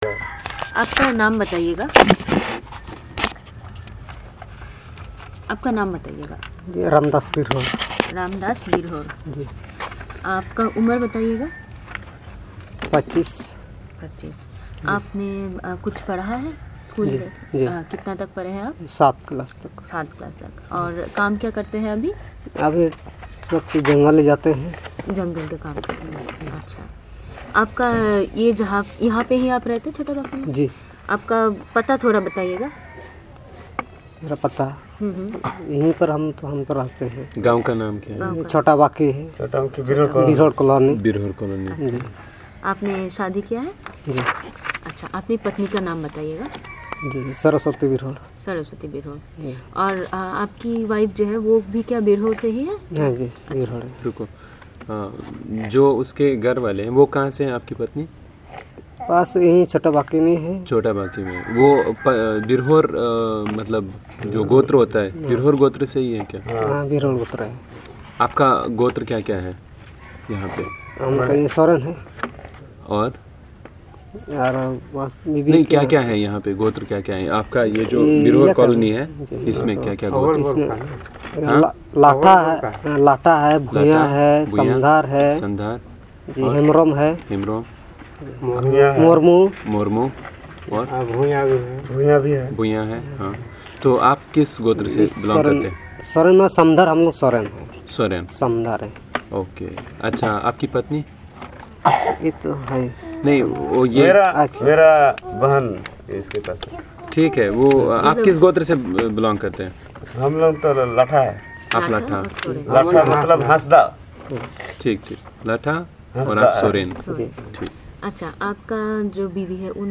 Informant profiling and discussion on daily affairs of Birhor community.